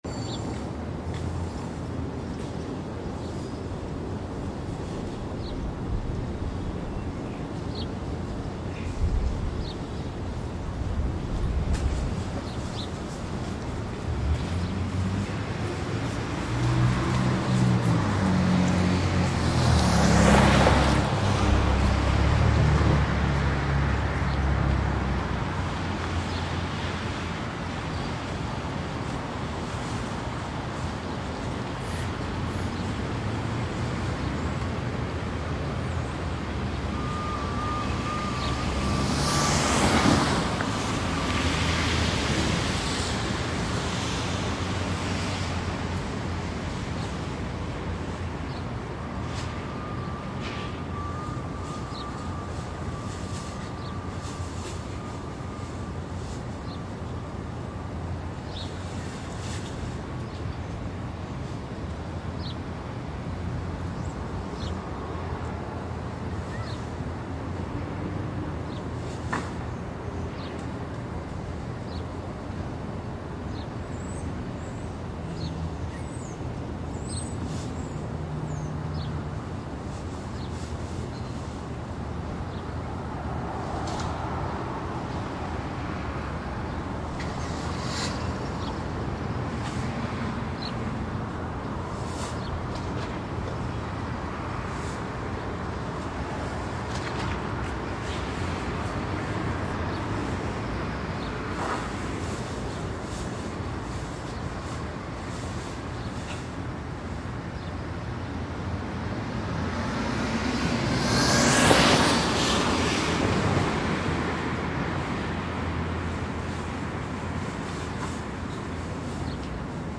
蒙特利尔 " 小巷街道 城市阴霾2 加拿大蒙特利尔
描述：胡同街道城市haze2蒙特利尔，Canada.flac
Tag: 蒙特利尔 街道 阴霾 胡同 城市 加拿大